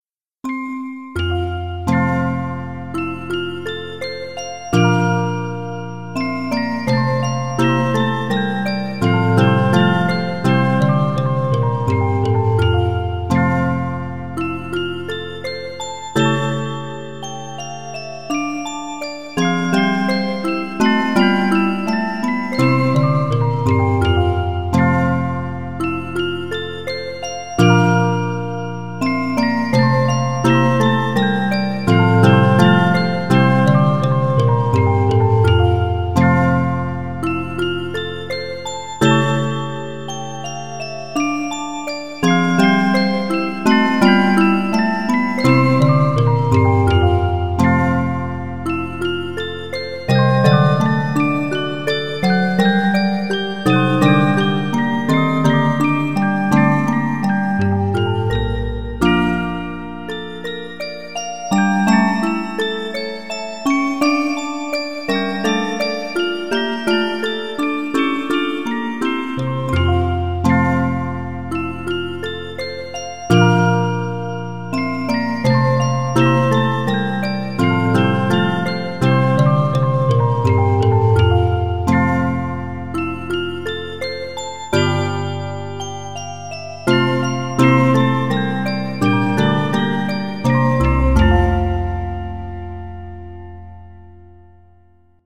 甘味で幻想的な曲はオルゴール音が良く合います。